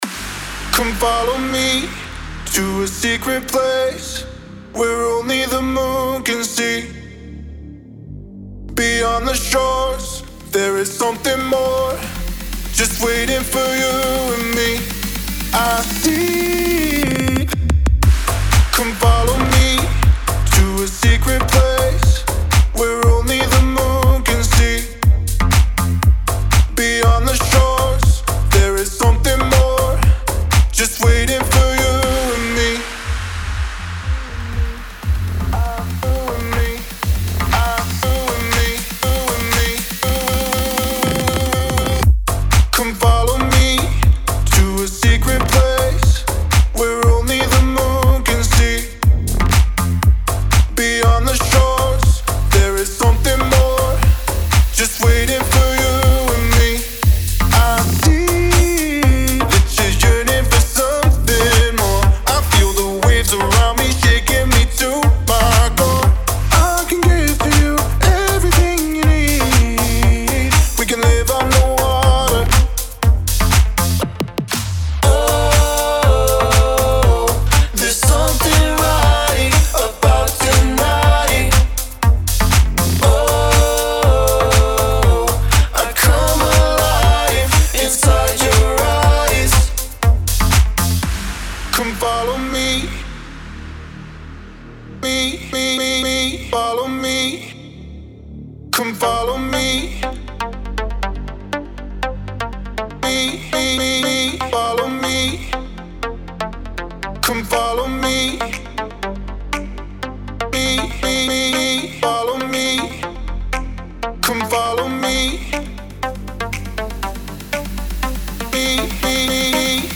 Танцевальный трек